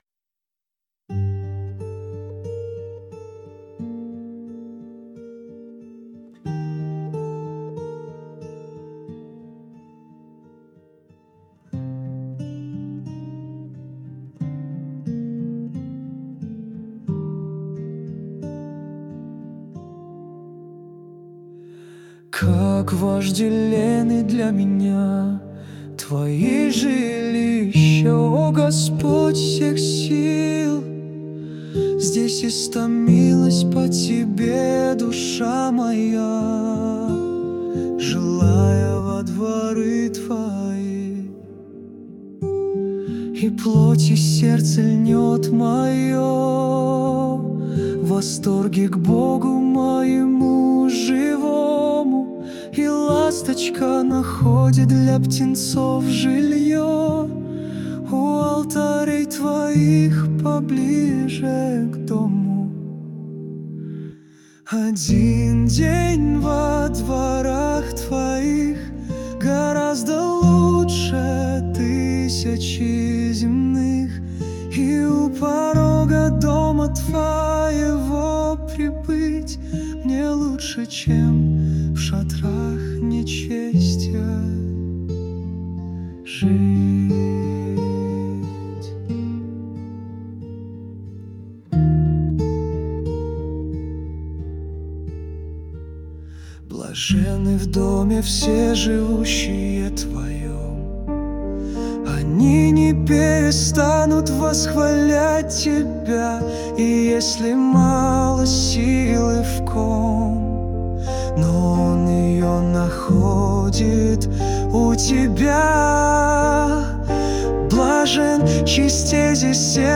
песня ai
124 просмотра 947 прослушиваний 50 скачиваний BPM: 91